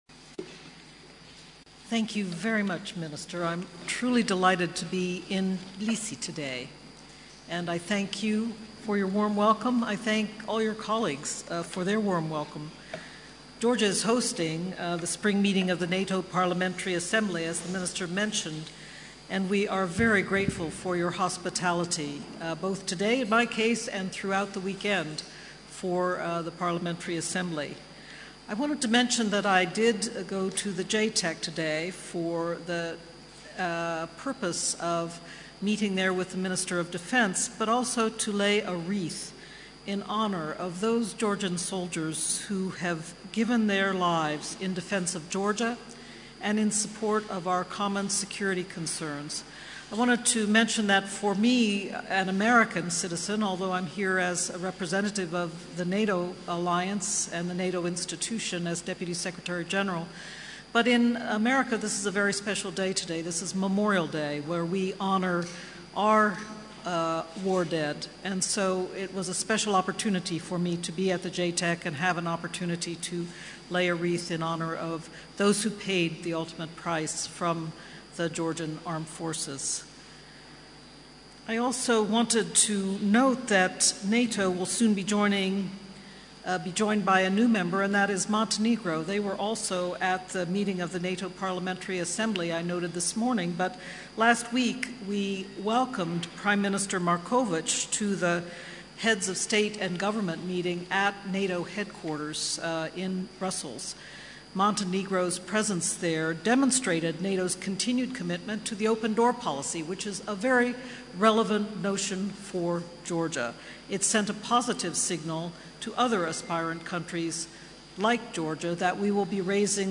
Deputy Secretary General addresses NATO Parliamentary Assembly in Tbilisi
Speech by NATO Deputy Secretary General Rose Gottemoeller at the NATO Parliamentary Assembly session